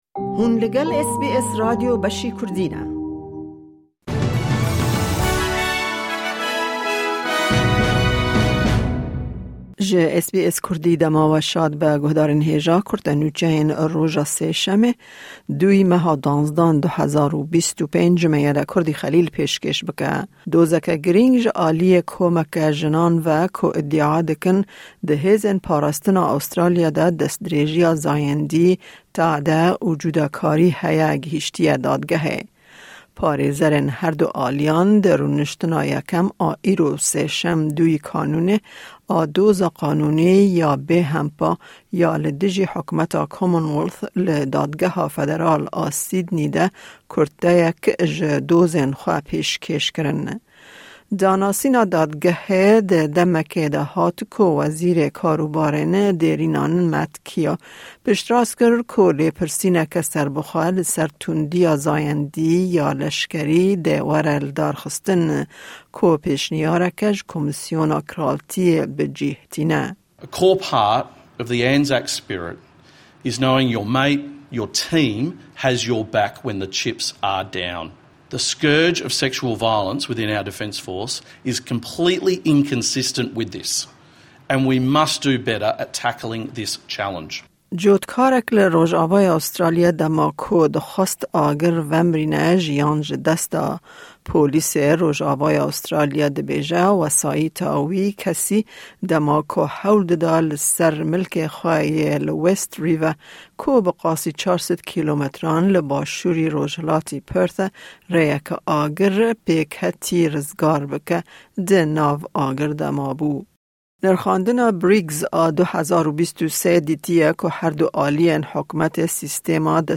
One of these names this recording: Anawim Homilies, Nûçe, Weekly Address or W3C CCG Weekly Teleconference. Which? Nûçe